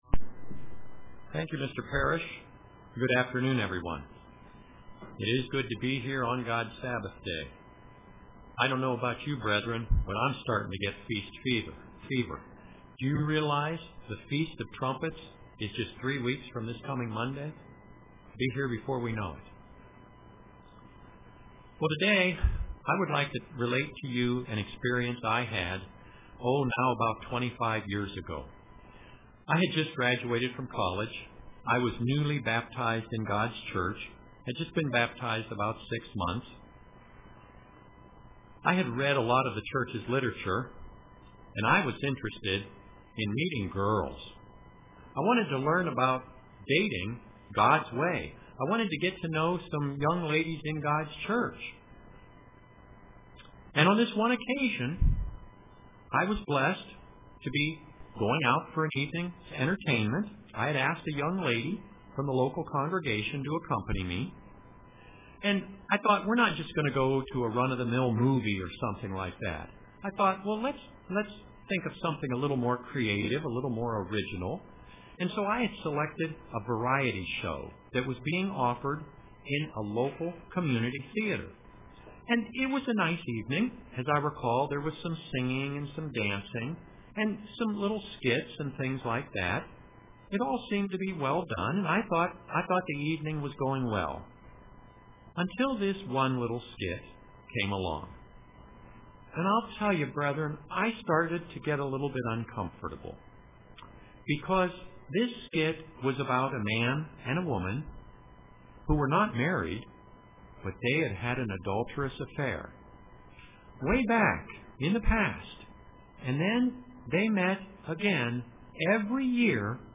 Print Power of a Christian Example UCG Sermon Studying the bible?